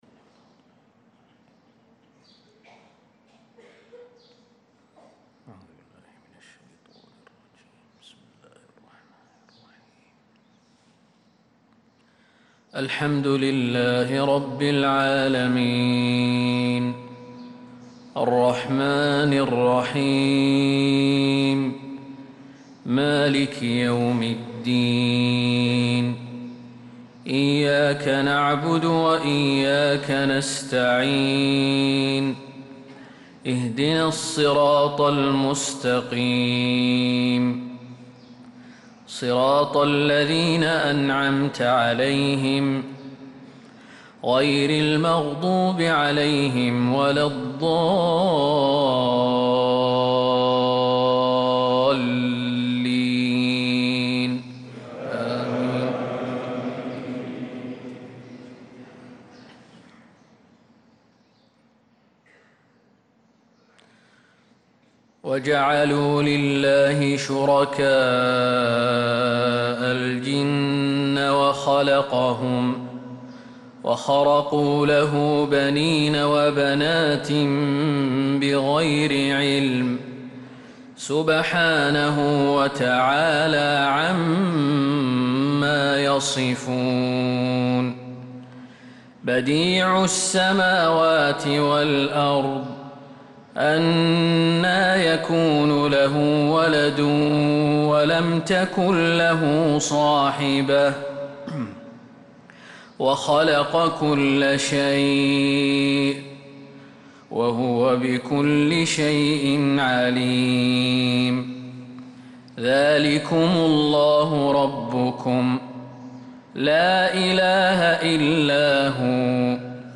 صلاة الفجر للقارئ خالد المهنا 2 ربيع الأول 1446 هـ
تِلَاوَات الْحَرَمَيْن .